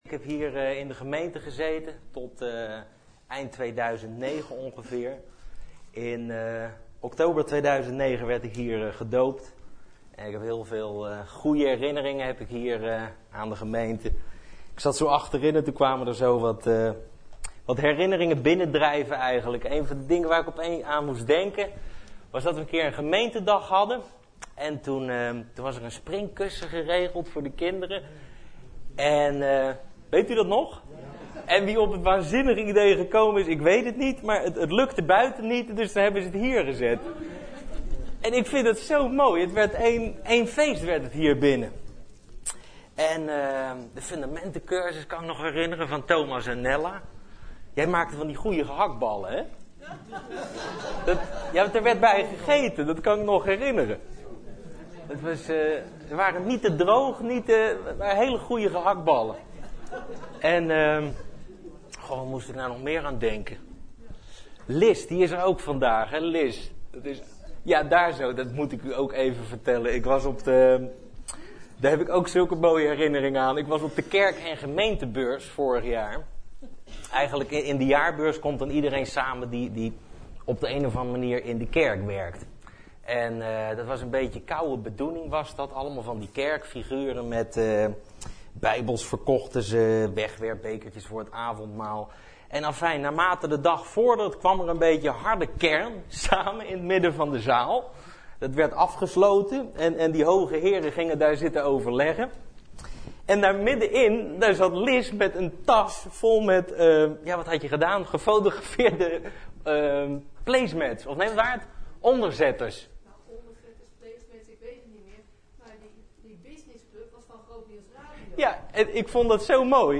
Overzicht van preken van Preken op Christengemeente Bethel